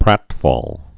(prătfôl)